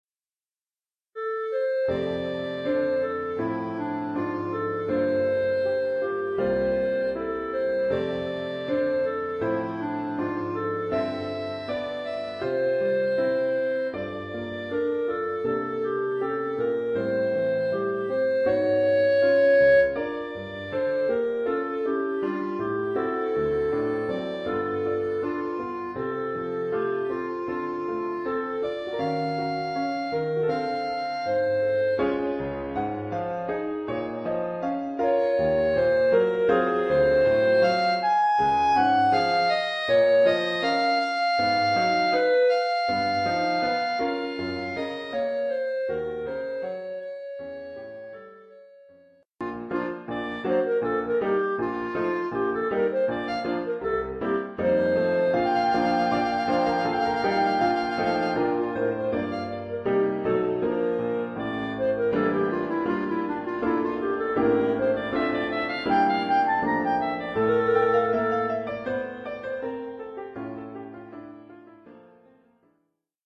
Oeuvre pour clarinette et piano.